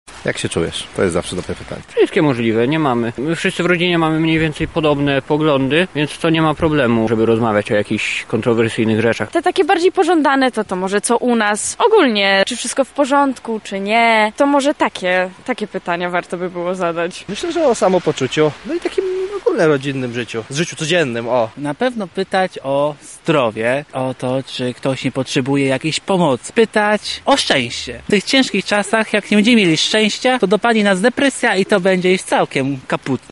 SONDA
Zakazanych pytań jest wiele, jednak Lublinianie zaproponowali również bezpieczne tematy, które powinny wypaś lepiej, niż te, o których wspomnieliśmy przed chwilą: